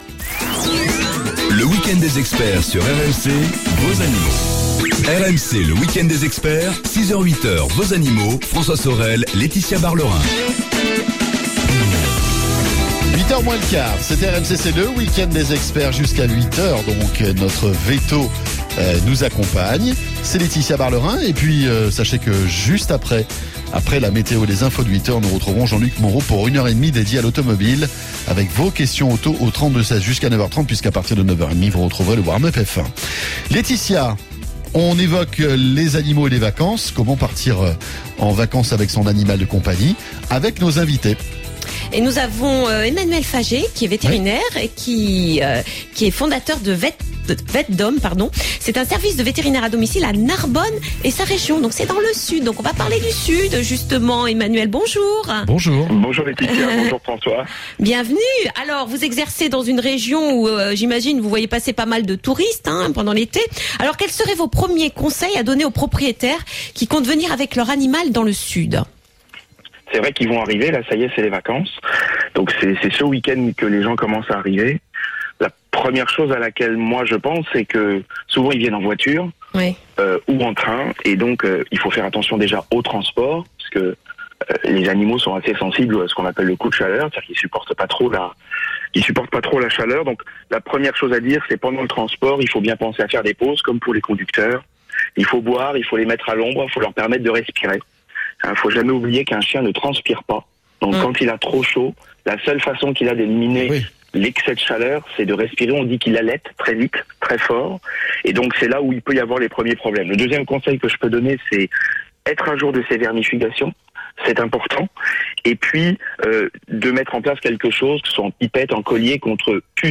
rmc_vetdom_veterinaire_partir_en_vacances.mp3